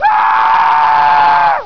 pain_6.wav